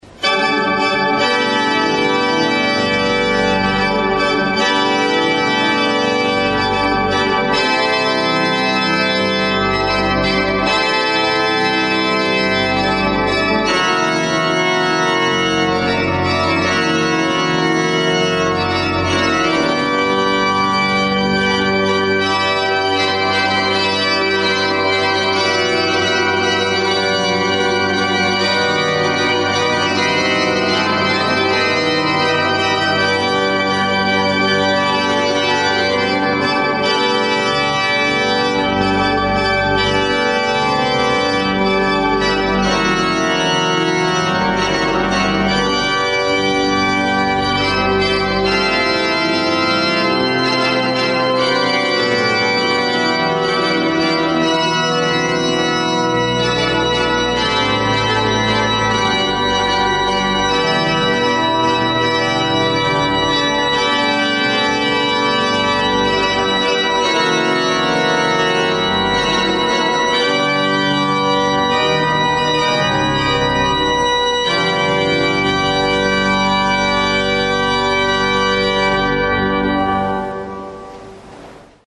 Orgelimprovisation Sollenau April 2008
Alle Orgelstücke sind in der neu renovierten Kirche St.Laurentius in Sollenau im April 2008 entstanden. Hauptaugenmerk habe ich dieses Mal auf Improvisationen im Stile einer modernen Toccata, Fanfare und Intrada gelegt.